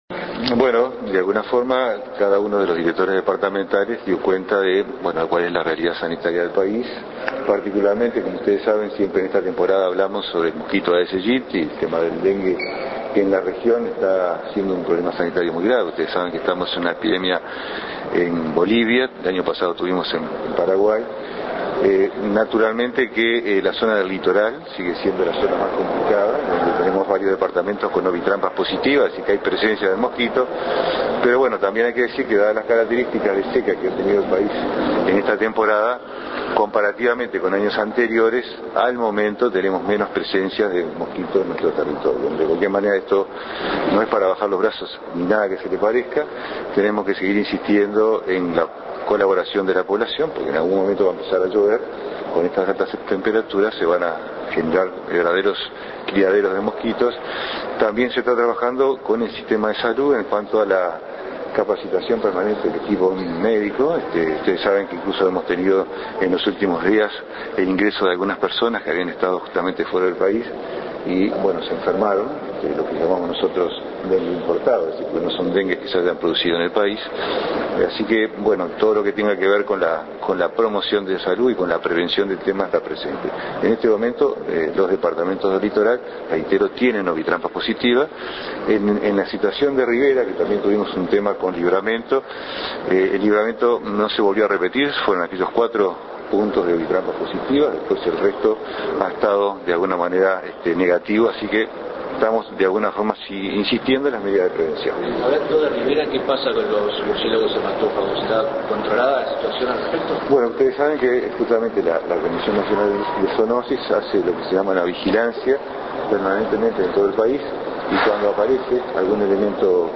Declaraciones del Director General de Salud del Ministerio de Salud Pública, Jorge Basso, tras reunirse con los Directores Departamentales.